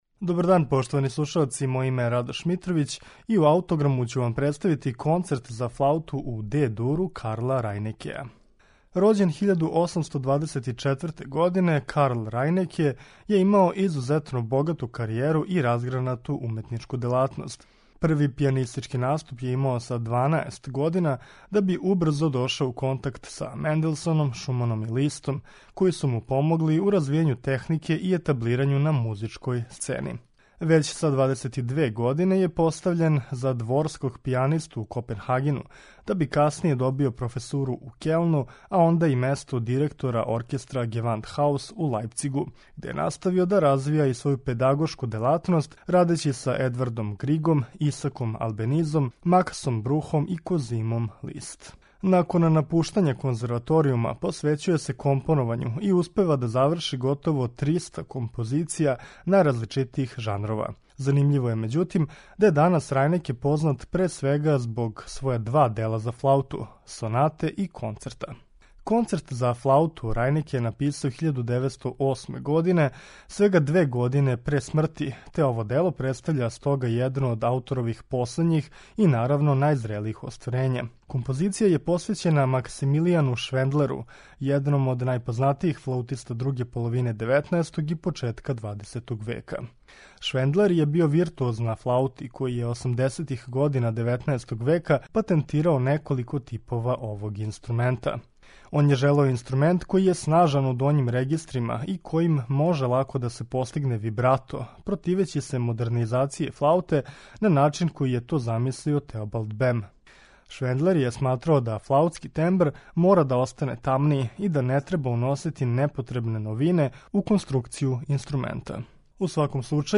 Концерт за флауту Карла Рајнекеа
Ово дело, које је значајно обогатило концертантну литературу за флауту, посвећено је Максимилијану Швендлеру, једном од најпознатијих виртуоза на овом инструменту из друге половине 19. и с почетка 20. века. Концерт за флауту, Карла Рајнекеа, слушаћете у извођењу Орела Николеа и оркестра Гевандхаус, под управом Курта Мазура.